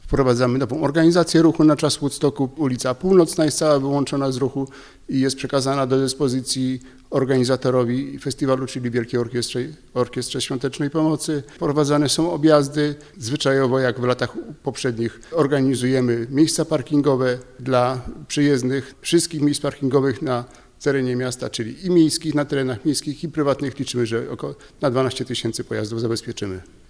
Miasto przygotowało około 12 tysięcy miejsc parkingowych. Niektóre ulice będą wyłączone z ruchu, na niektórych obowiązywać będą ograniczenia. – Wszystko po to, by zapewnić maksimum bezpieczeństwa – mówi zastępca burmistrza Zbigniew Biedulski: